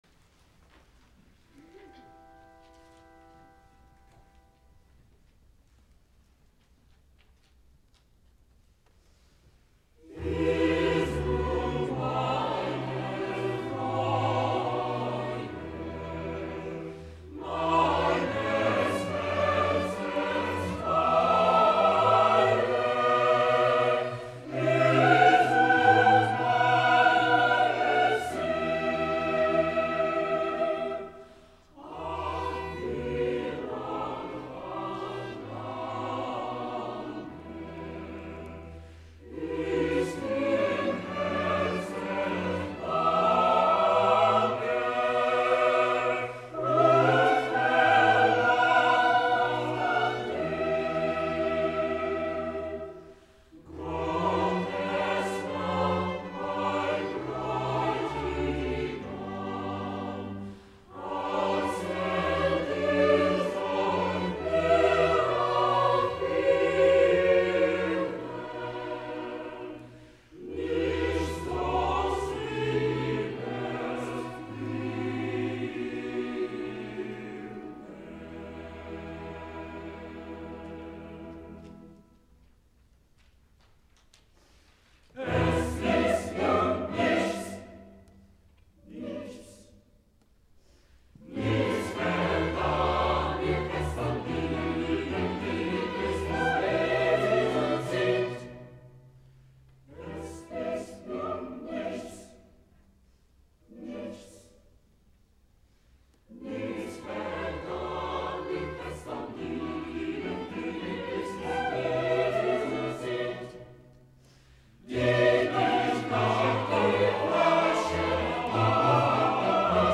第２５回演奏会音源の公開棚